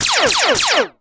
EnemyLasers1.wav